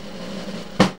JAZZ FILL 6.wav